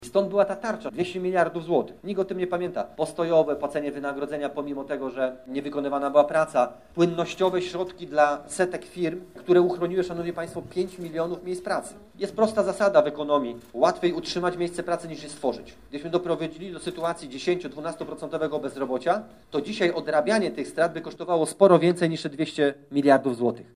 Minister Waldemar Buda spotkał się z mieszkańcami Łowicza.